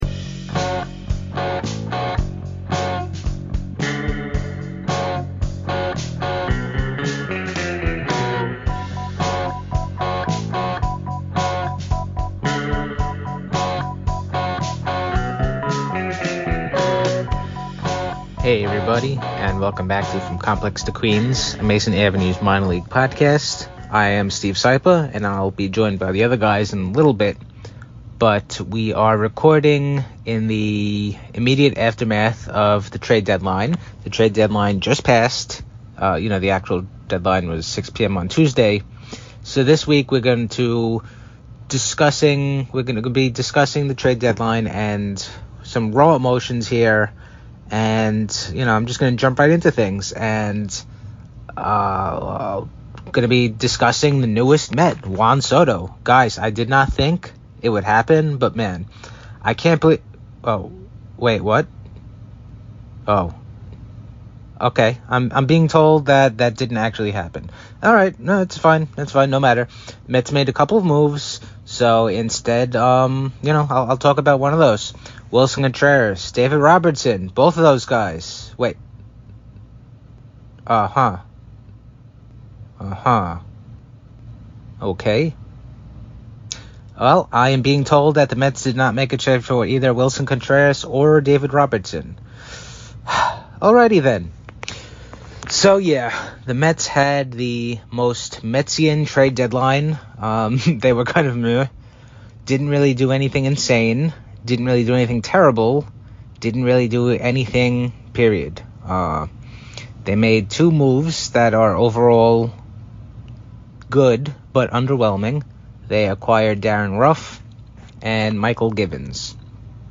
These pieces were recorded right after the deadline passed, so there are pure, unadulterated, unfiltered emotions that you’ll be getting. There may or may not be some cursing as well.